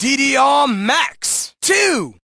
Root > content > SFX & Announcers > DDR Extreme SFX